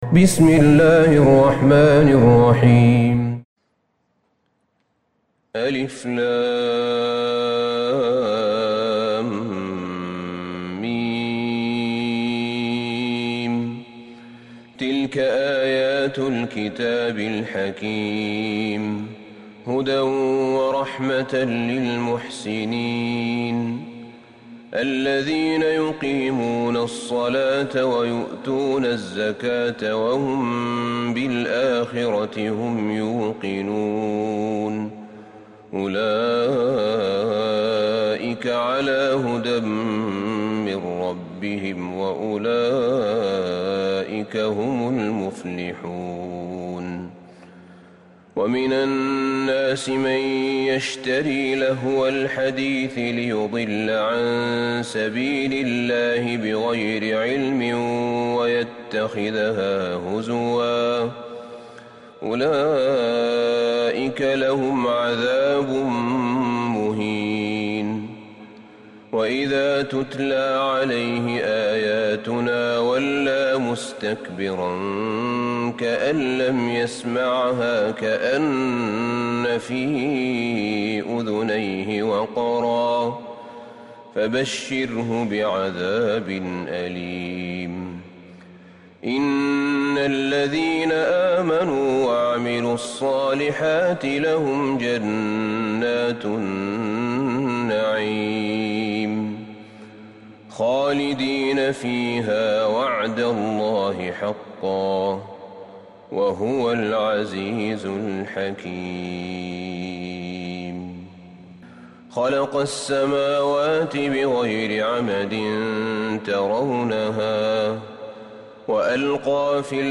سورة لقمان Surat Luqman > مصحف الشيخ أحمد بن طالب بن حميد من الحرم النبوي > المصحف - تلاوات الحرمين